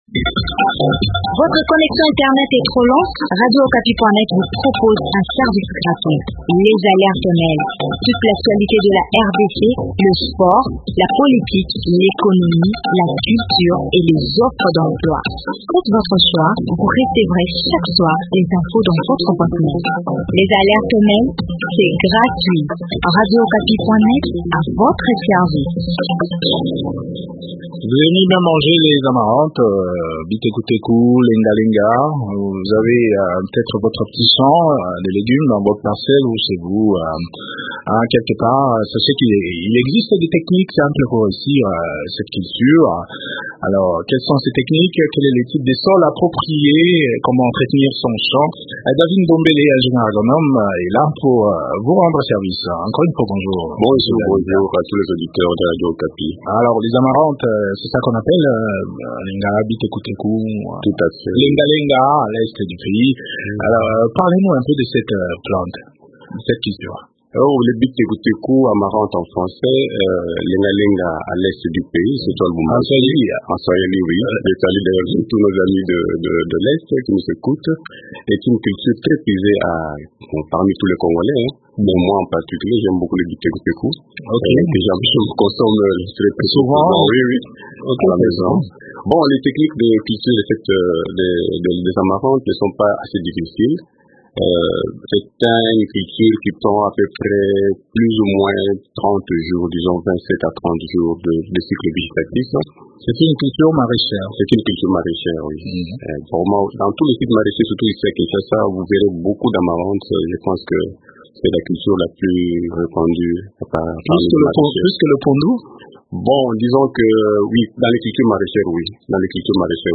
ingénieur agronome